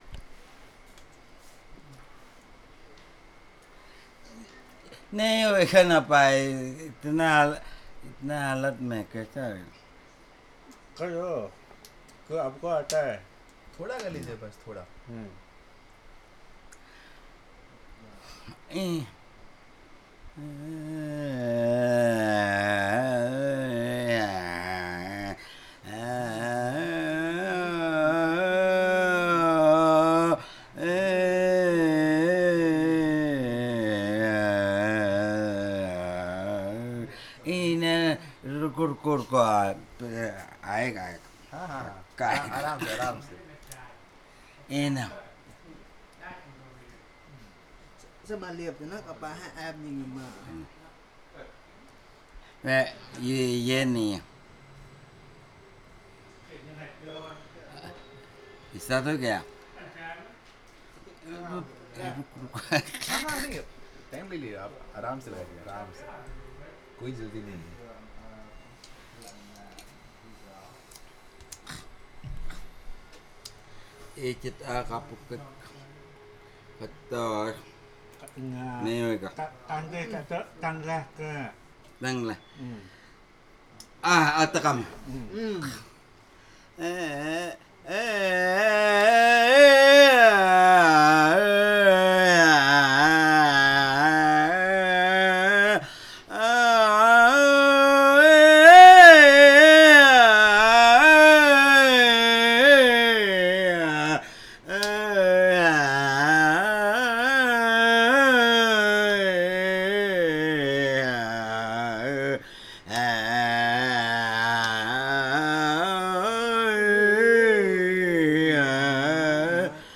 Performance of a Lullaby in Luro